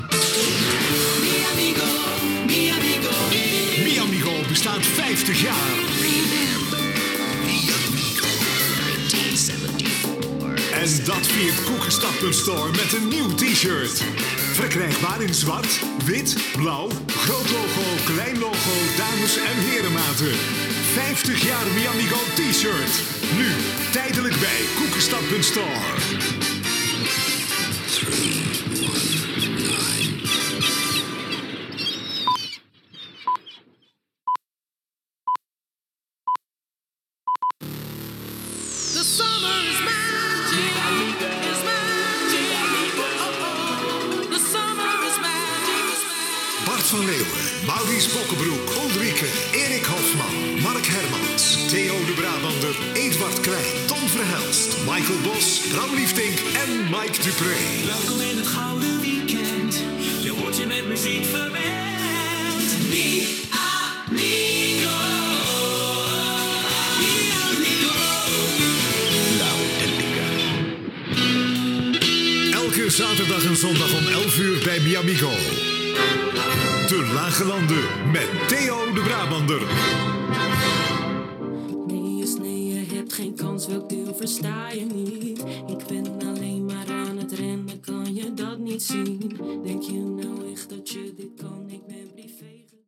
Indicatiu, promoció de les samarretes de l'emissora, indicatiu (programació parlada en flamenc)